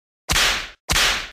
Whip
Category: Sound FX   Right: Personal